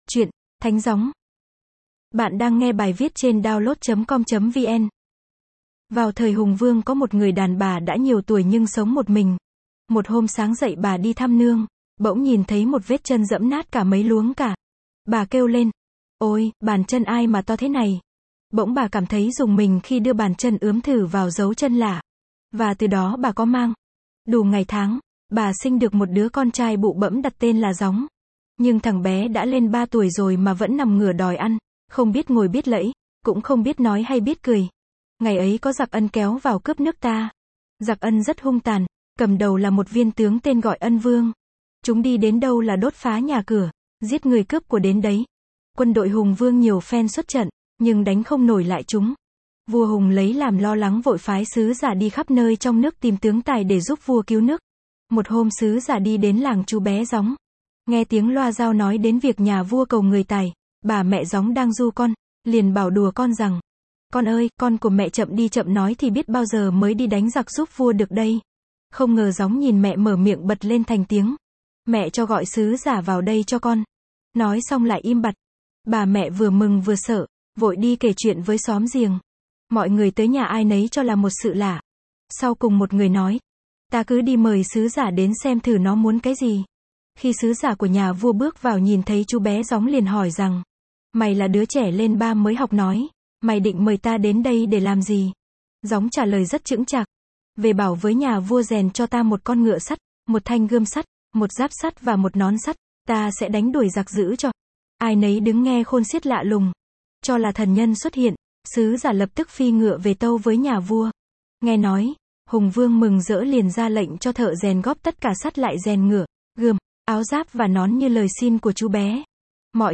Sách nói | Thánh gióng